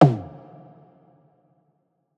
PERC - SLEEP.wav